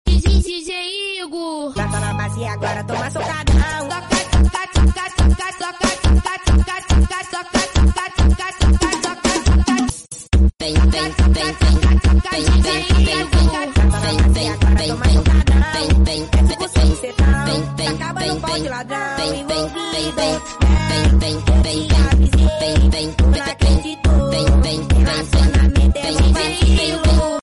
hahahaha 🤣🤣 sound effects free download